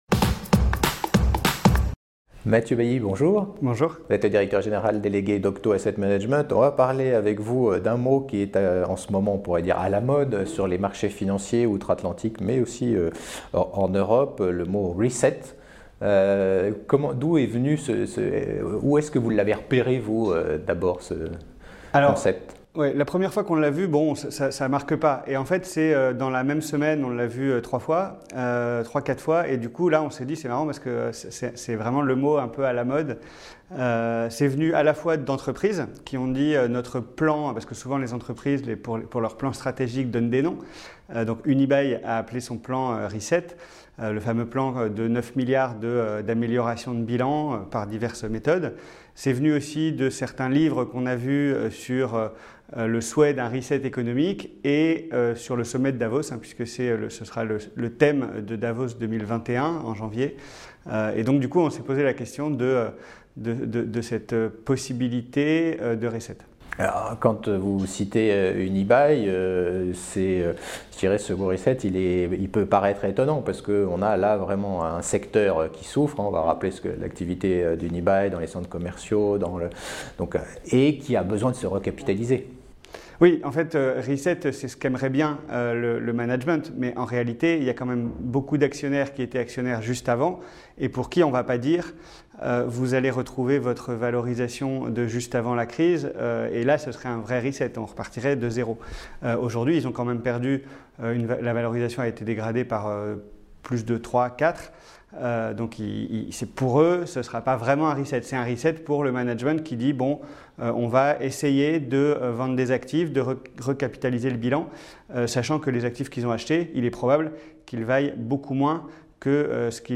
J’en parle avec mon invité